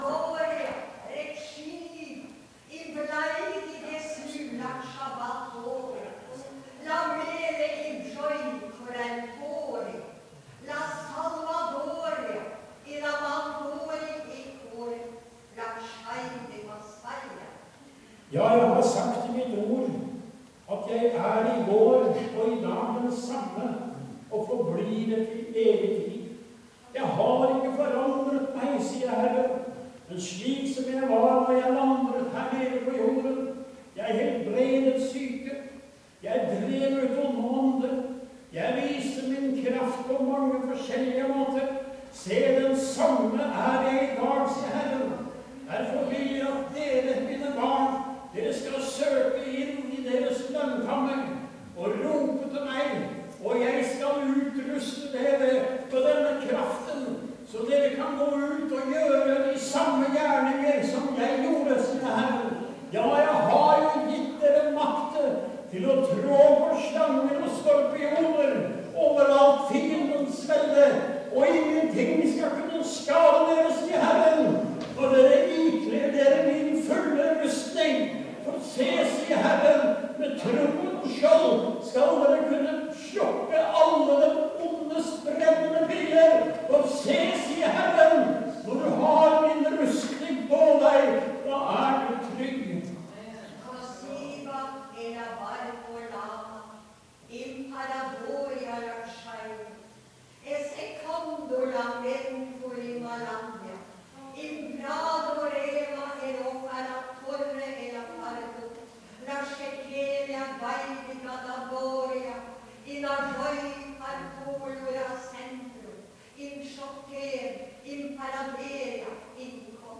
Møte i Maranata 6.10.2013.